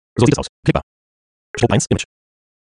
Aber zu den Tondateien - die sind irgrndwie so wie 5x schneller abgespielt - kannst Du mal danach schauen?
Also der muss so schnell sein, damit man schnell durch den Wald aus wiederholendem Text durchpflügen kann.